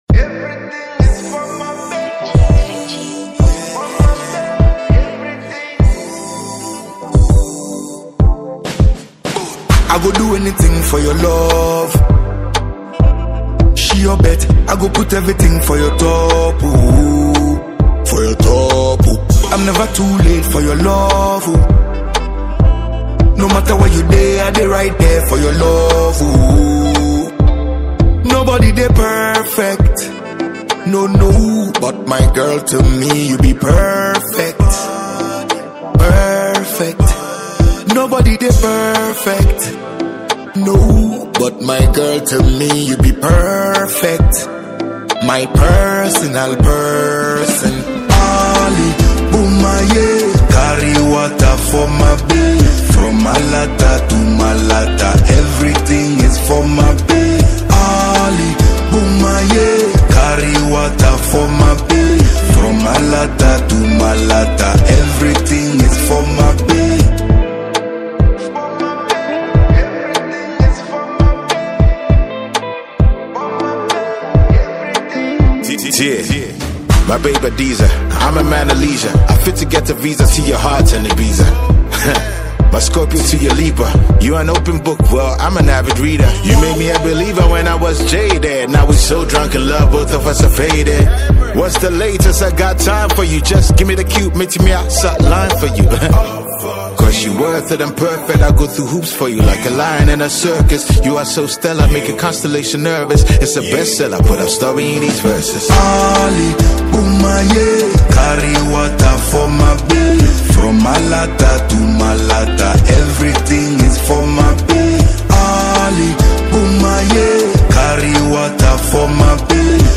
a Ghanaian afrobeats highlife and dancehall musician